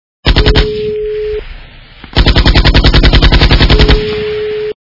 При прослушивании Автомат - Калашникова качество понижено и присутствуют гудки.
Звук Автомат - Калашникова